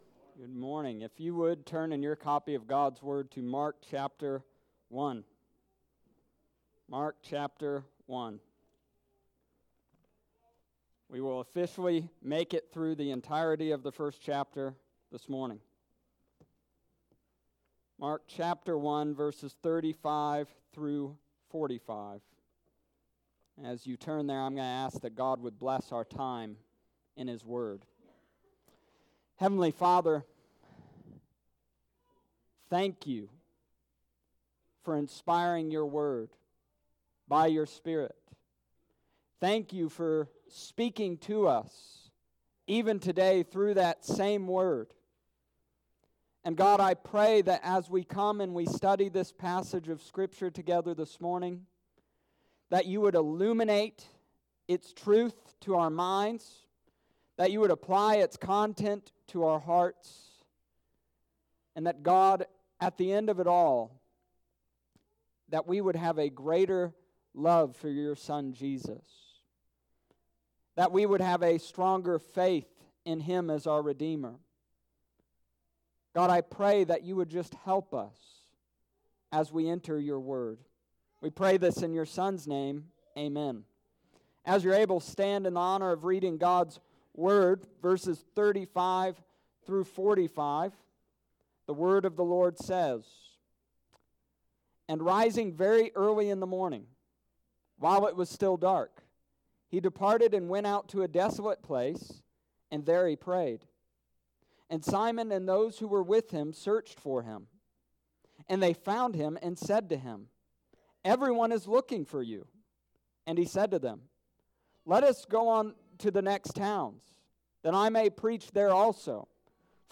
Summary Of Sermon: This week we discussed Jesus departure from Capernaum and ministry Thereafter.